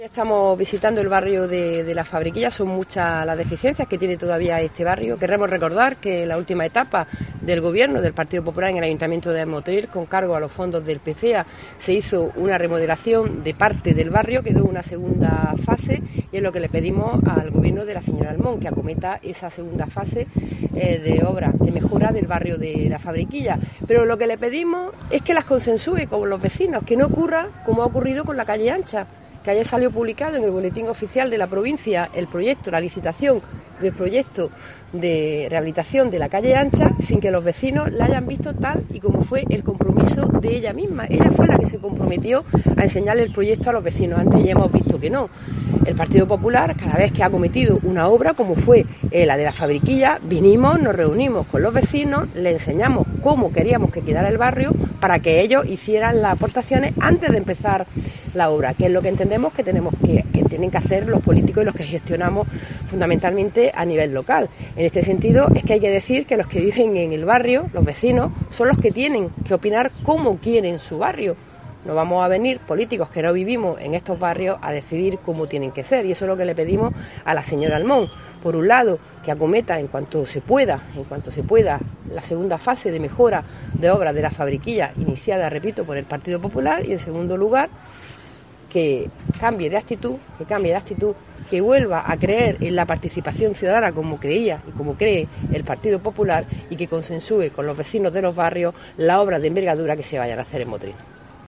El PP de Motril visita el barrio de la Fabriquilla y pide a la alcaldesa Flor Almón que continúe los trabajos de mejoras iniciados por los populares. Luisa García Chamorro, portavoz municipal